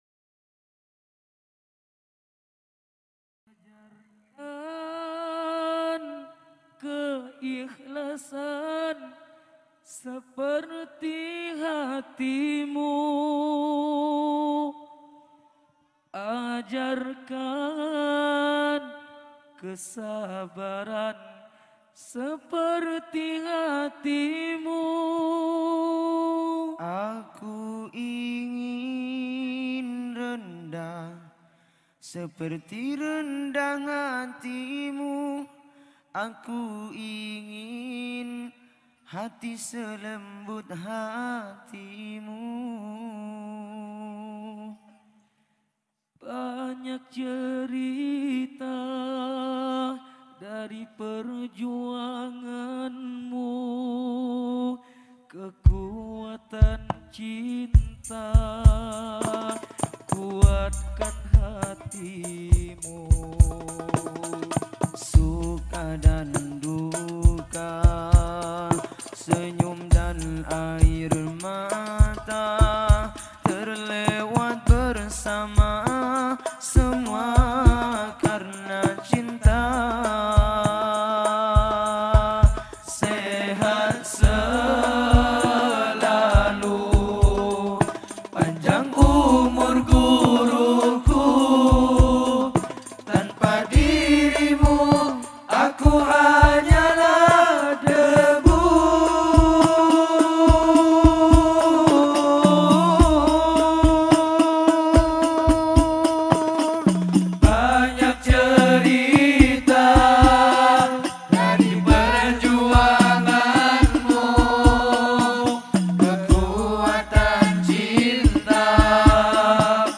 Blog Tempat Berbagi Sholawat Mulai Dari Lirik Sholawat